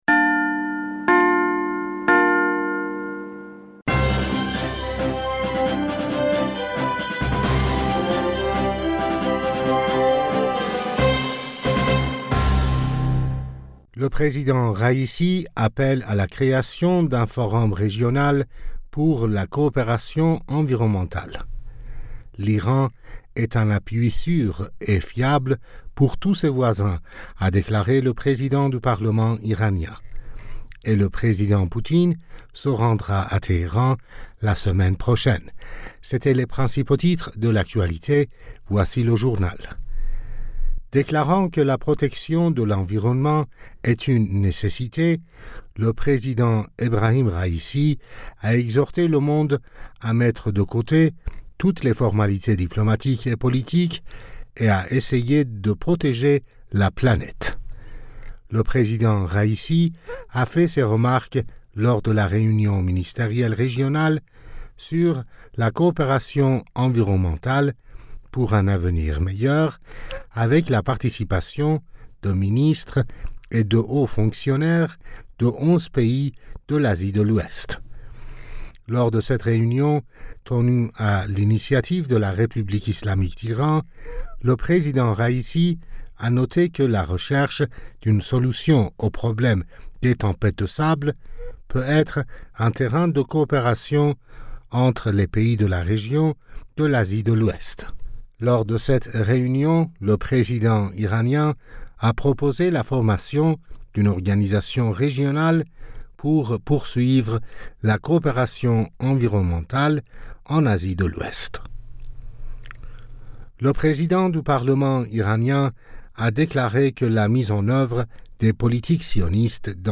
Bulletin d'information Du 12 Julliet